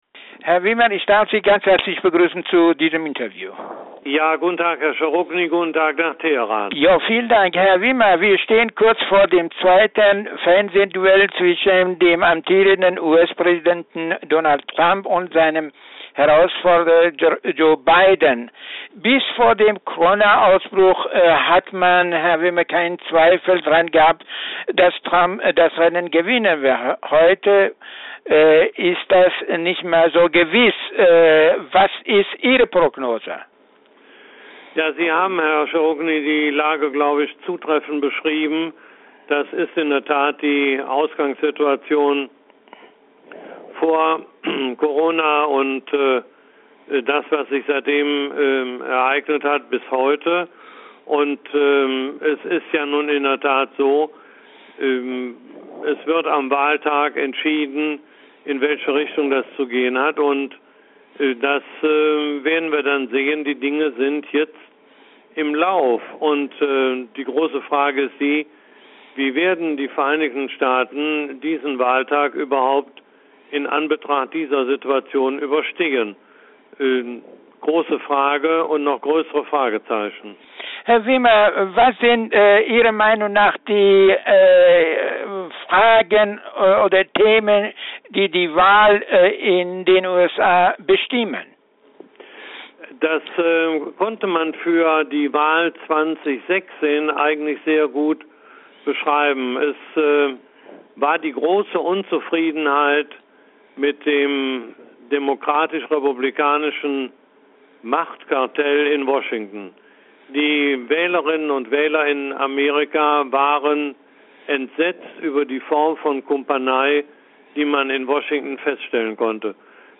Wird der amtierende Präsident der Vereinigten Staaten von Amerika Donald Trump wierdergewählt, oder wird der Kandidat der demokratischen Partei Joe Biden das Renennen gewinnen? Ein Interview mit dem CDP-Politiker Willy Wimmer